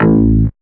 BAS_Bass Pinkstr.wav